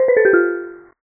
BoxFall.ogg